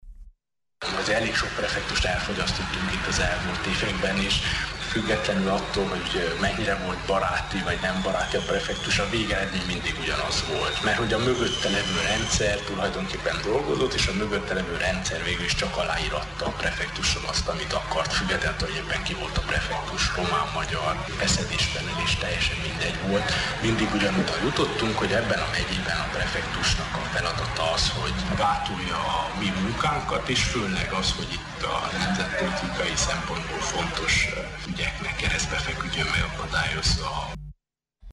Székelyföldön a kormányt képviselő prefektusoknak az a dolga, hogy gátolják az önkormányzatok munkáját, nyilatkozta híradónknak Antal Árpád.